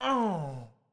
SFX_Mavka_Hit_Voice_04.wav